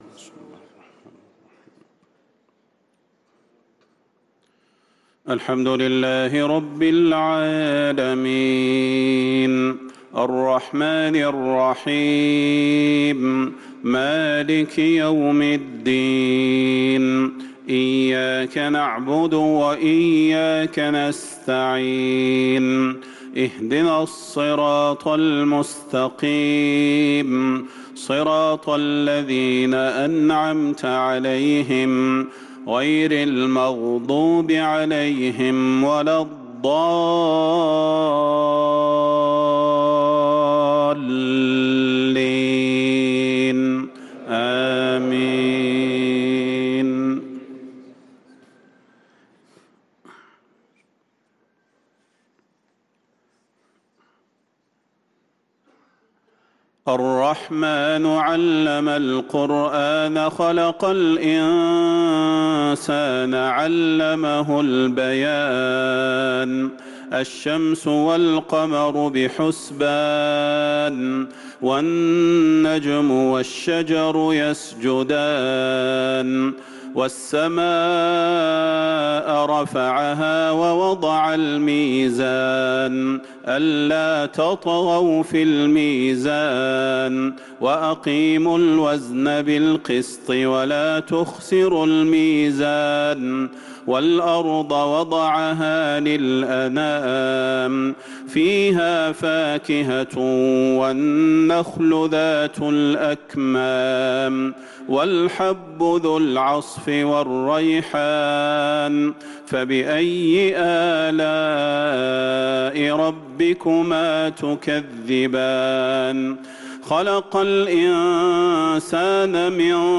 صلاة العشاء للقارئ صلاح البدير 15 جمادي الآخر 1445 هـ